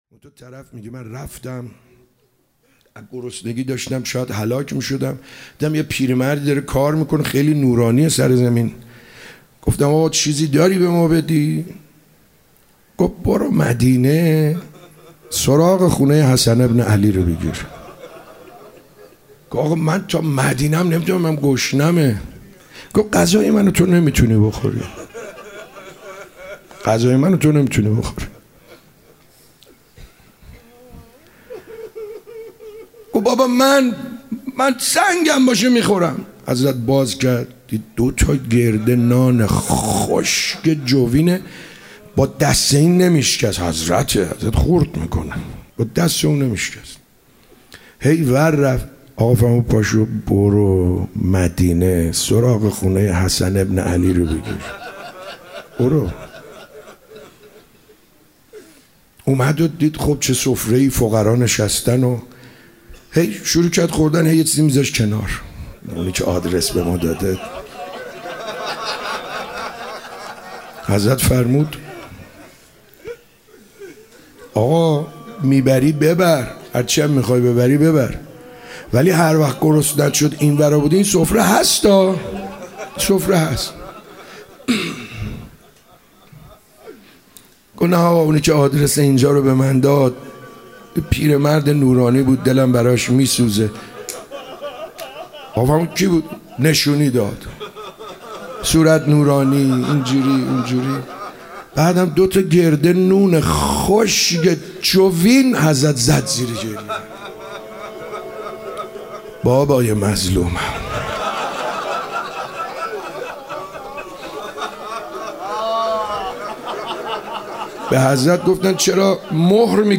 شب 17 رمضان 97 - صحبت - بخش دوم